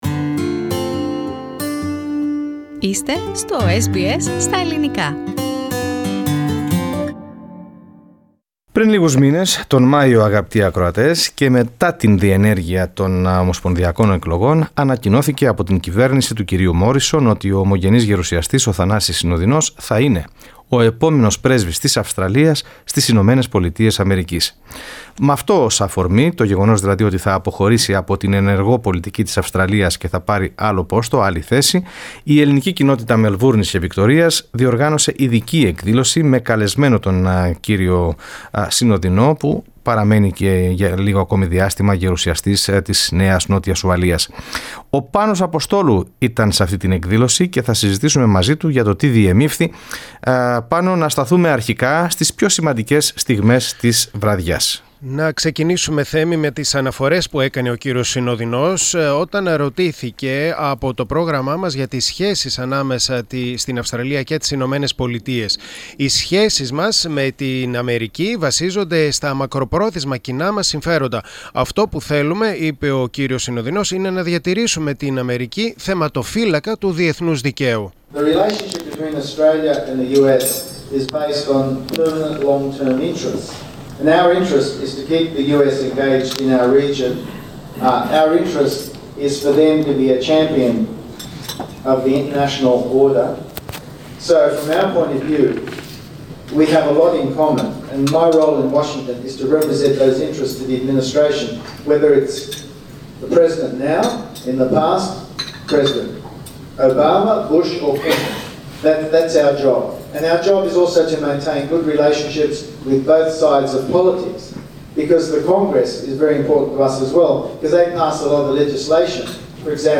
Λίγες ημέρες μετά τις ομοσπονδιακές εκλογές της 18ης Μαΐου ανακοινώθηκε από την κυβέρνηση της Αυστραλίας ότι επόμενος Πρέσβης της Αυστραλίας στις ΗΠΑ θα είναι ο ομογενής γερουσιαστής Θανάσης Συνοδινός. Με αυτό το γεγονός ως αφορμή, η Ελληνική Κοινότητα Μελβούρνης διοργάνωσε ειδική εκδήλωση με καλεσμένο τον γερουσιαστή της Νέας Νότιας Ουαλίας. Το SBS Greek ήταν εκεί.